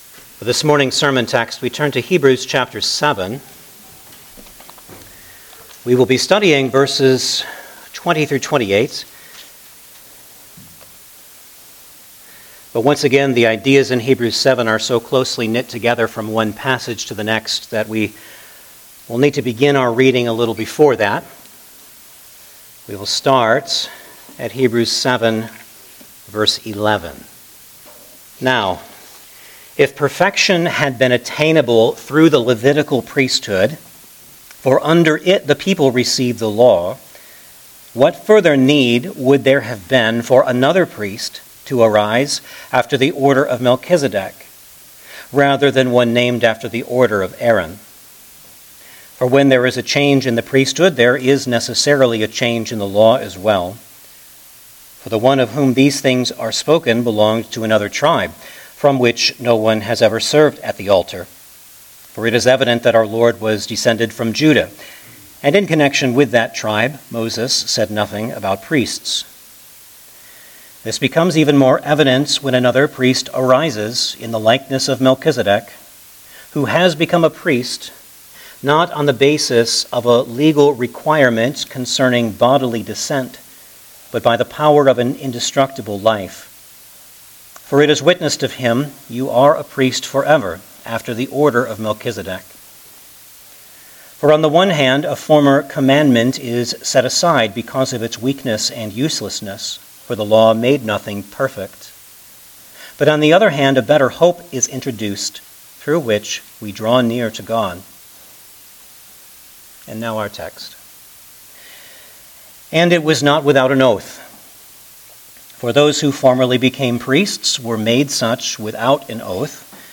Hebrews Passage: Hebrews 7:20-28 Service Type: Sunday Morning Service Download the order of worship here .